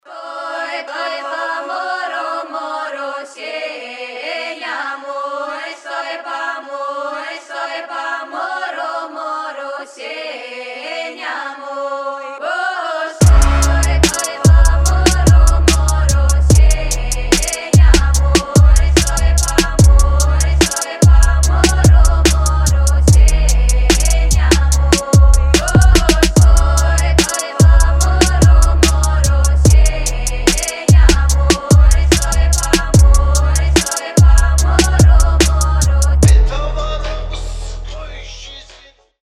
• Качество: 192, Stereo
хор
биты